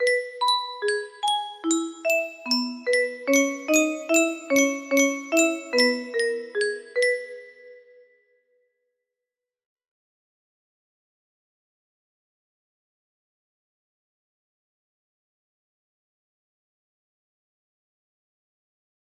daisy bell demo music box melody
Full range 60